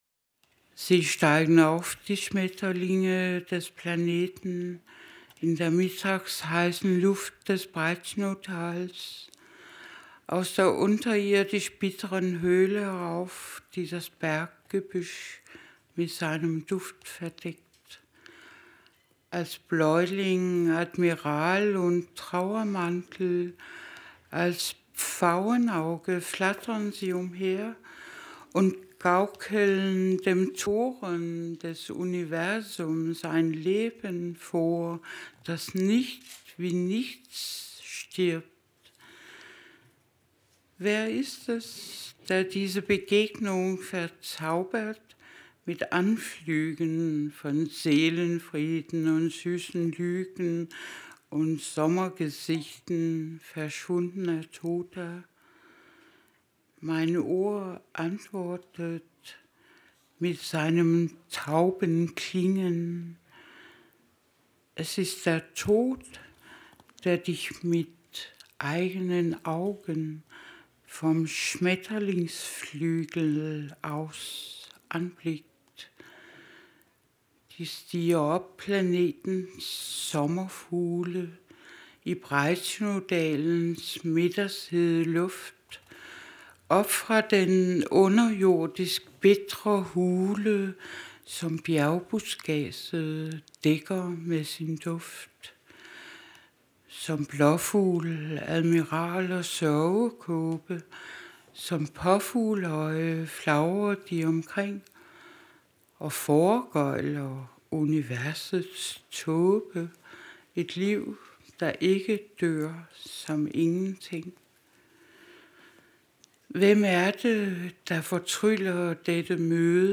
Lesung von Inger Christensen in der literaturWERKstatt Berlin zur Sommernacht der Lyrik – Gedichte von heute